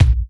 Kick_33.wav